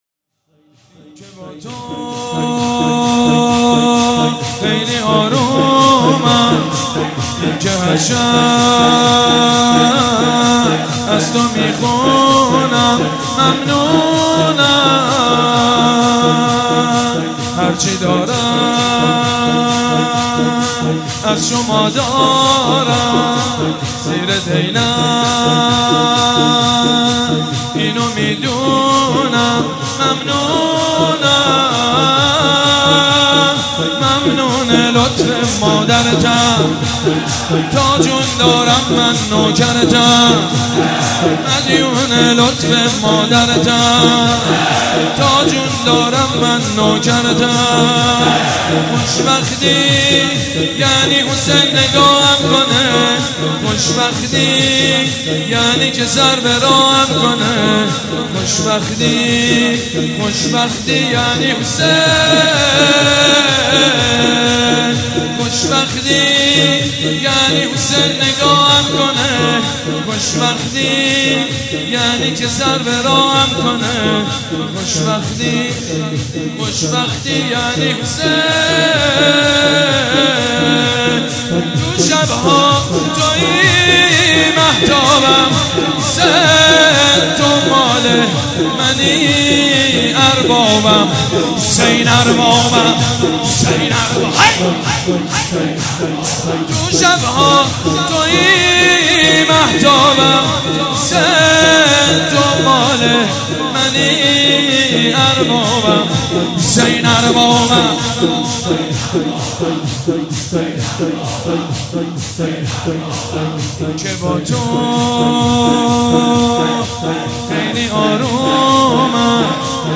مجموعه مراسم بنی فاطمه در شب هشتم محرم 93
مجنون باید با لیلا باشه ماهی باید (شور زیبا)